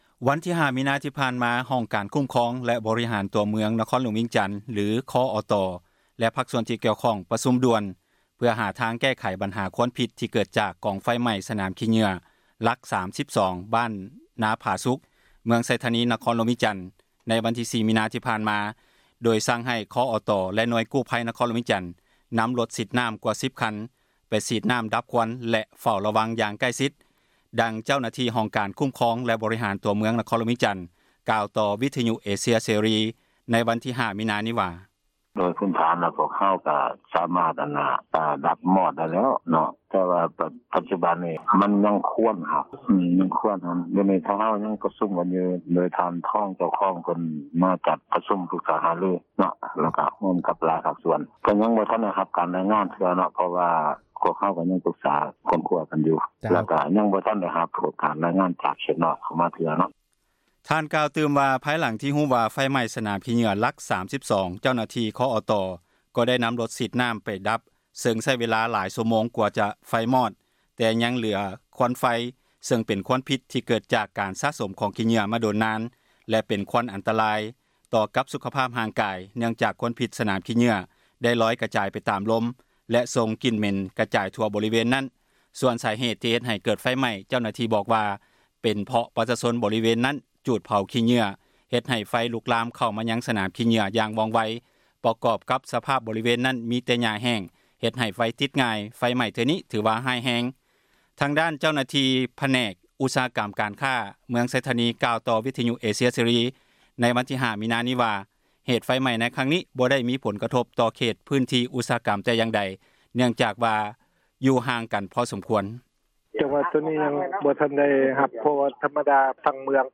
ໂດຍສັ່ງໃຫ້ ຄ ບ ຕ ແລະໜ່ວຍກູ້ພັຍ ນະຄອນຫຼວງວຽງຈັນ ນຳຣົດສີດນໍ້າ ກວ່າ 10 ຄັນ ໄປສີດນໍ້າດັບຄວັນ ແລະ ເຝົ້າລະວັງຢ່າງໃກ້ຊິດ. ດັ່ງເຈົ້າໜ້າທີ່ ຫ້ອງການຄູ້ມຄອງ ແລະ ບໍຣິຫານ ຕົວເມືອງ ນະຄອນຫຼວງ ວຽງຈັນ ກ່າວຕໍ່ ວິທຍຸເອເຊັຽເສຣີ ເມື່ອວັນທີ 05 ມີນາ ນີ້ວ່າ: